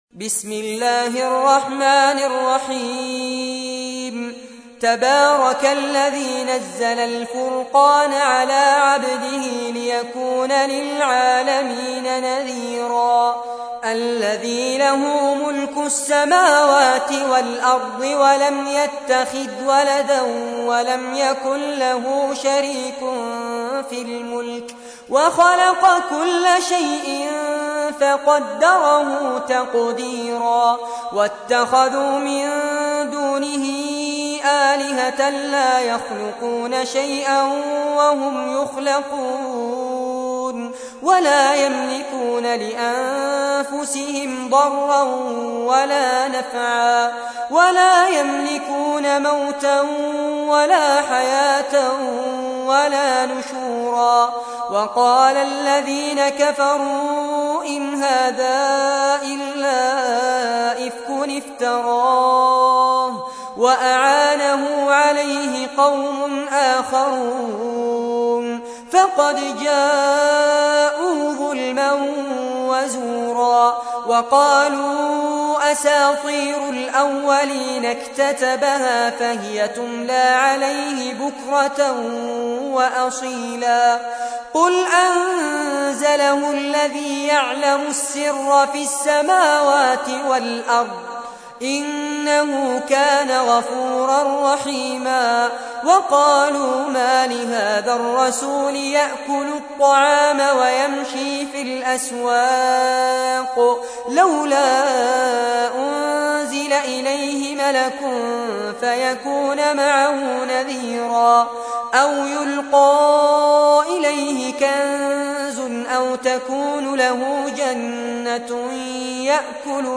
تحميل : 25. سورة الفرقان / القارئ فارس عباد / القرآن الكريم / موقع يا حسين